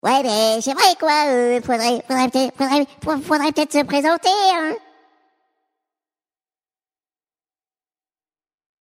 Voix off
cartoon 2
Doublage